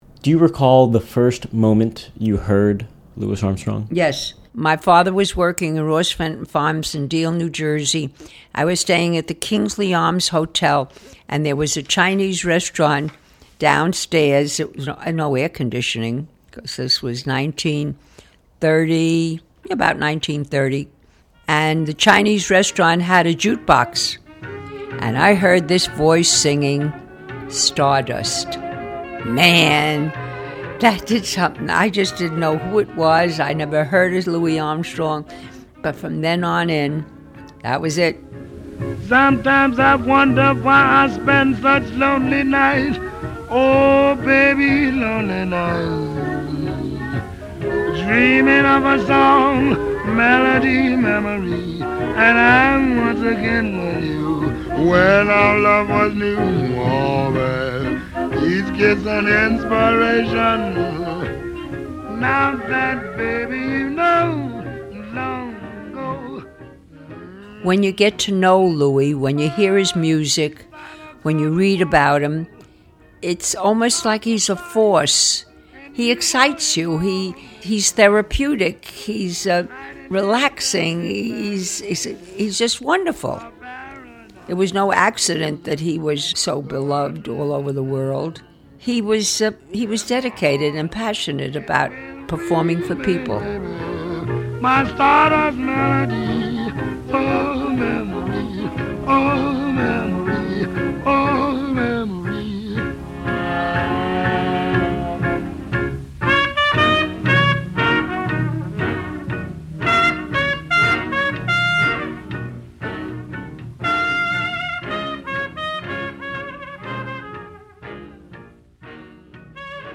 Music credit:Excerpt of “Stardust” composed by Hoagy Carmichael and written by Mitchell Parrish from the box set, Louis Armstrong: Portrait of the Artist as a Young Man [1923-1934], used courtesy of Sony Music Entertainment.